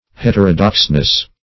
heterodoxness - definition of heterodoxness - synonyms, pronunciation, spelling from Free Dictionary
-- Het"er*o*dox`ness, n.